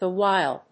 アクセントthe whíle